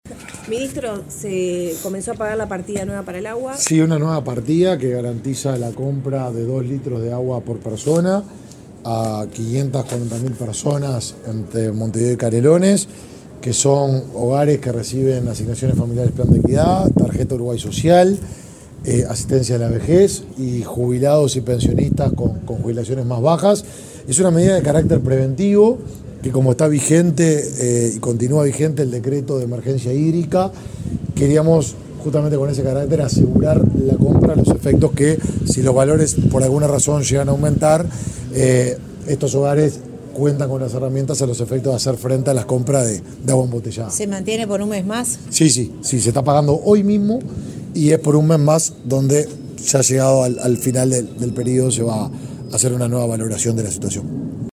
Declaraciones del titular del Mides, Martín Lema